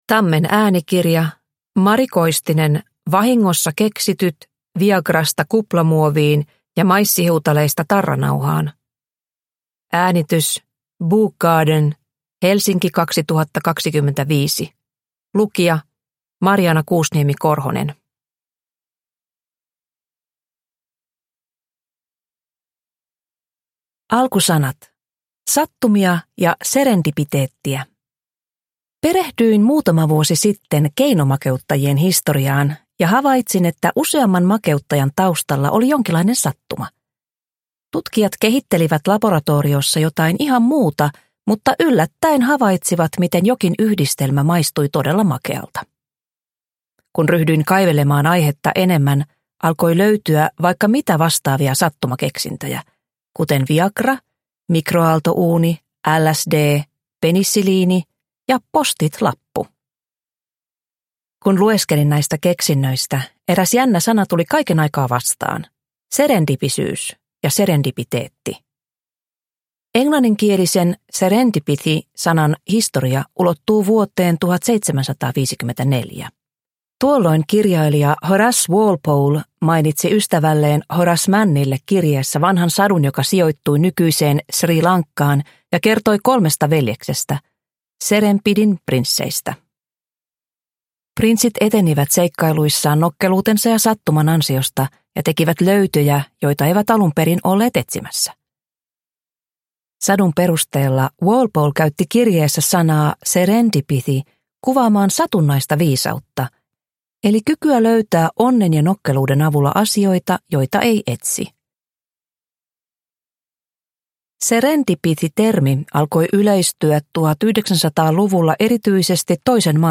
Vahingossa keksityt – Ljudbok